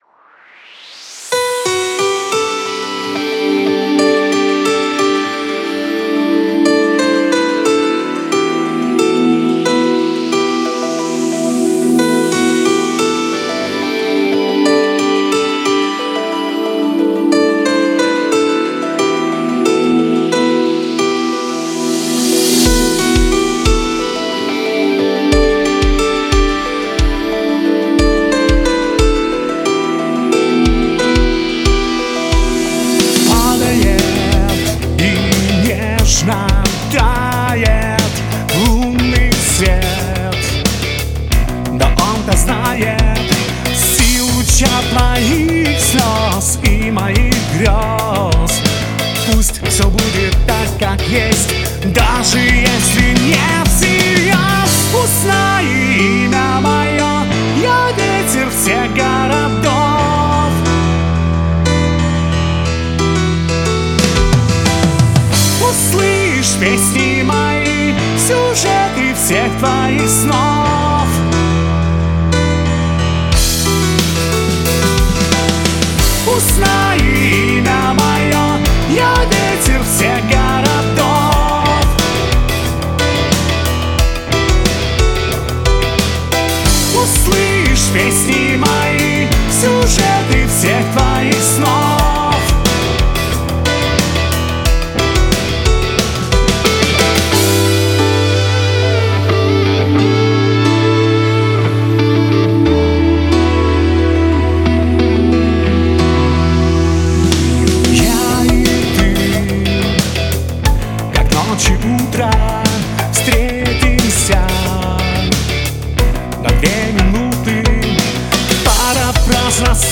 Поп-рок.